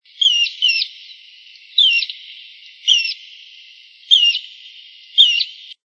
Sooty-fronted Spinetail (Synallaxis frontalis)
Life Stage: Immature
Country: Argentina
Location or protected area: Reserva Ecológica Costanera Sur (RECS)
Condition: Wild
Certainty: Recorded vocal